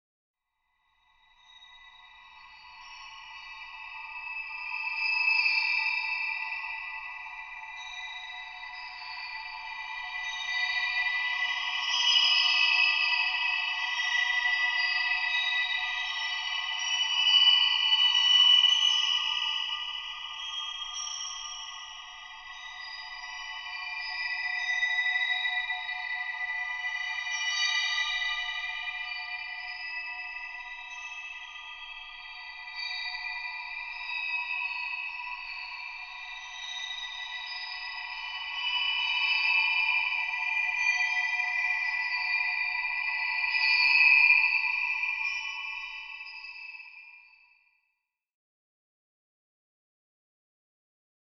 Horror Wind Chimes Eerie Sound
horror